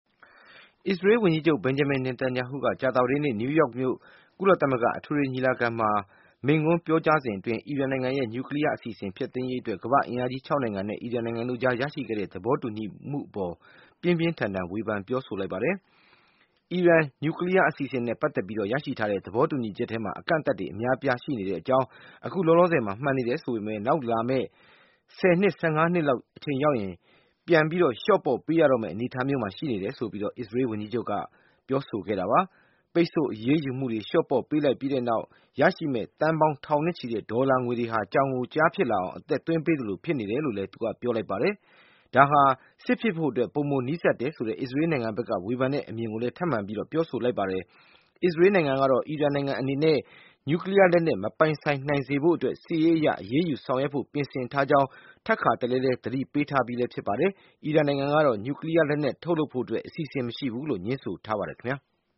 အစ္စရေးဝန်ကြီးချုပ် ကုလမိန့်ခွန်း